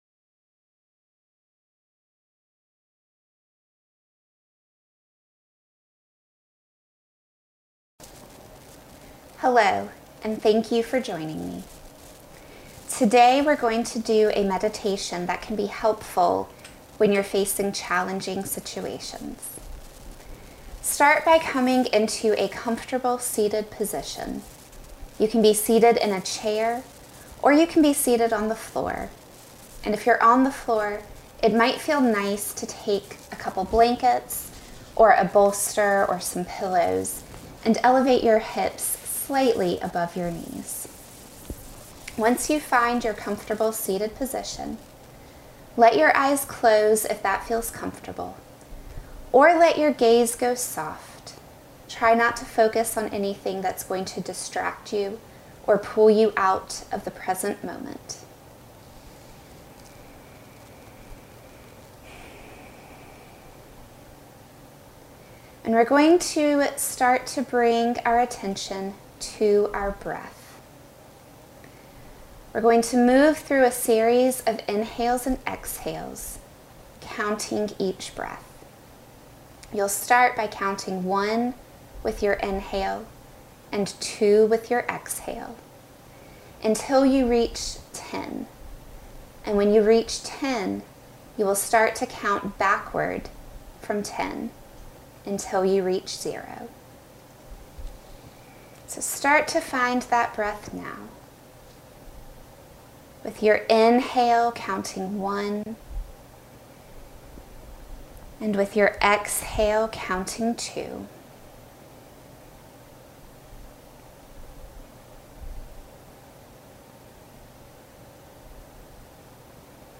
A Meditation for Challenging Times
meditation-for-challenging-times.mp3